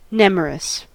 Ääntäminen
Synonyymit wooded forested Ääntäminen US Tuntematon aksentti: IPA : /ˈnɛmərəs/ Haettu sana löytyi näillä lähdekielillä: englanti Käännöksiä ei löytynyt valitulle kohdekielelle.